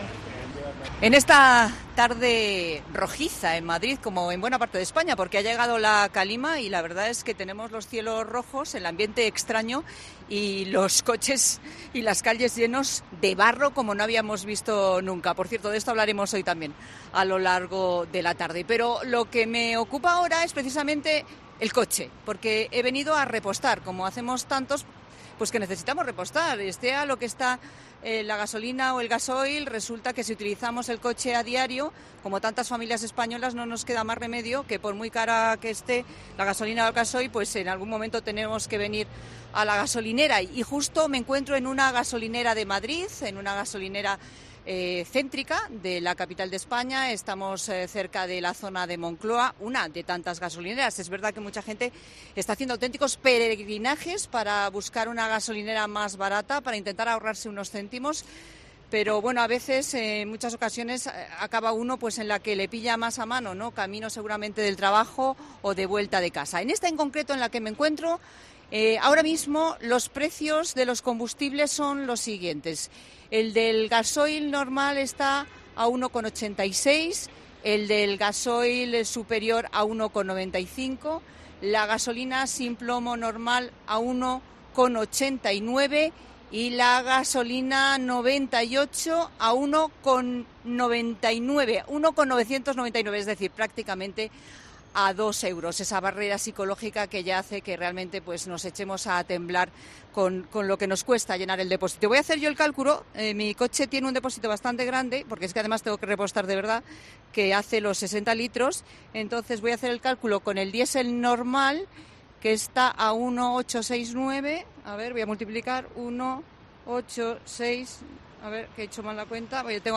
Resolvemos esta cuestión desde una gasolinera madrileña